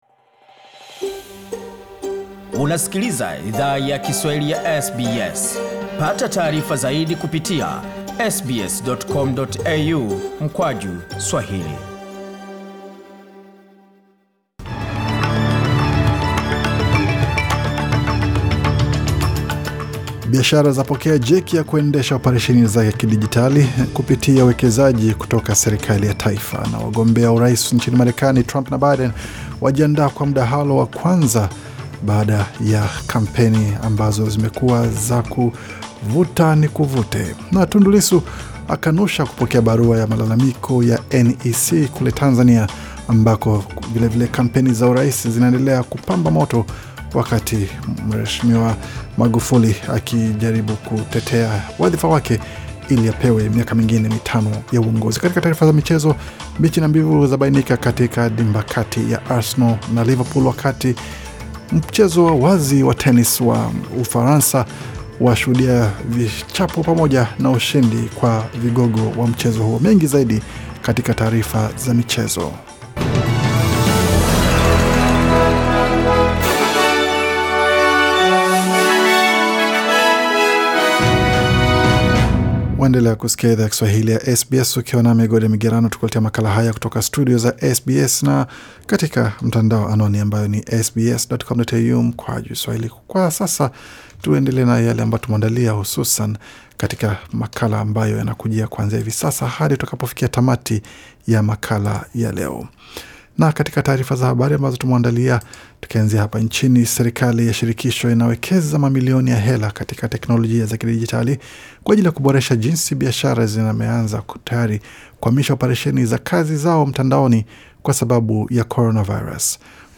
Taarifa ya habari 29 Septemba 2020